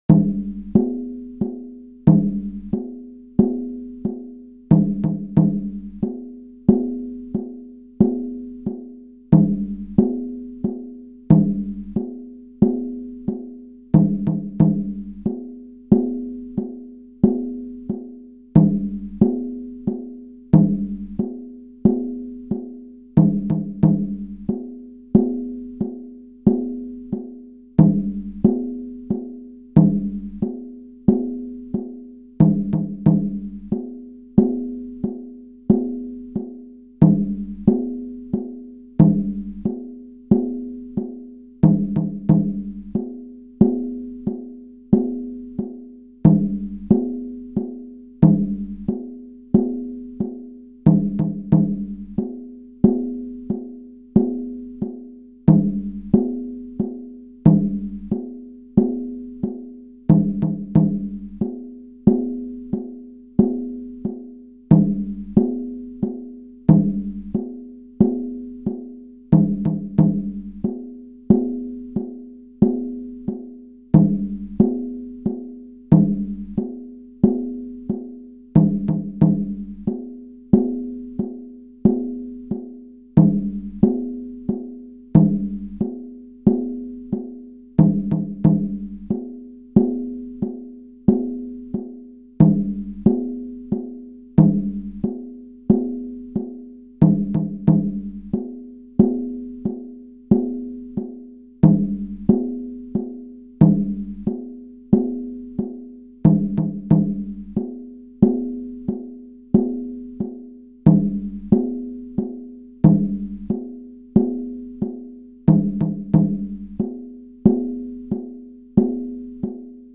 Veamos algo sobre el ritmo: 14 partes, aquí representadas por una corchea, con figuras variadas y ritmo de la melodía no demasiado servil al ritmo, que es:
Dum Ta Ka Dum Ka Ta Ka Du-ma Dum Ka Ta Ka Ta Ka
Debajo rayas de 4 subcompases: 14 = 3+4+3+4 pulsos.